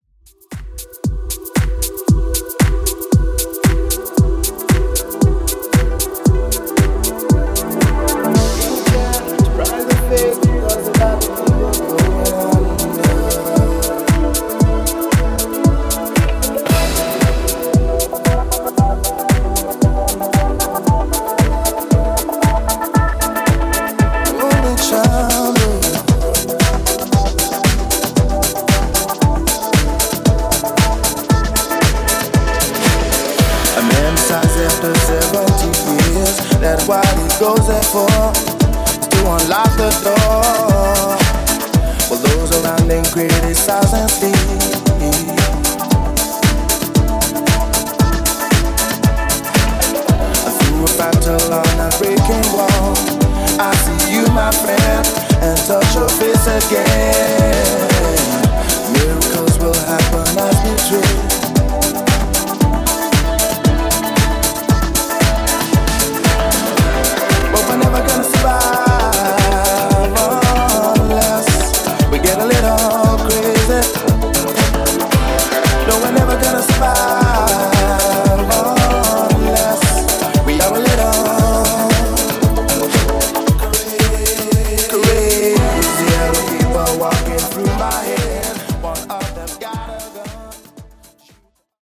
Classic House Mix)Date Added